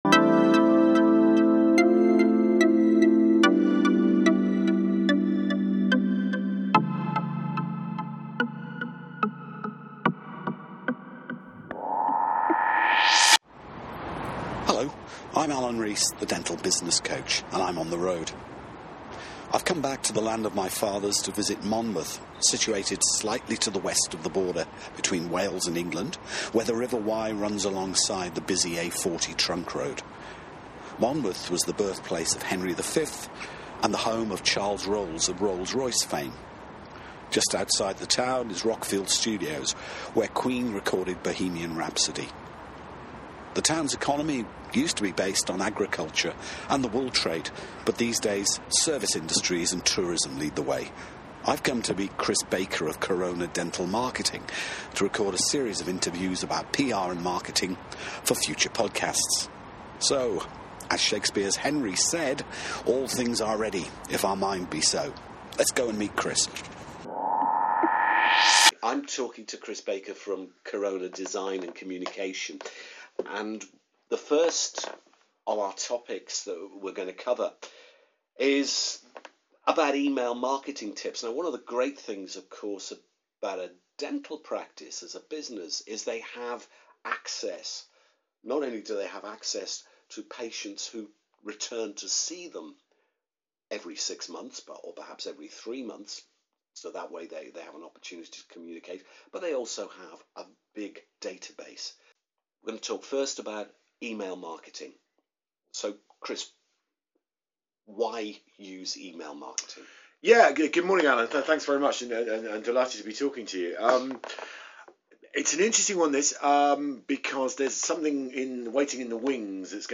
In the first of a series of Podcast conversations about PR and Marketing for Dentists